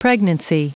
Prononciation du mot pregnancy en anglais (fichier audio)
Prononciation du mot : pregnancy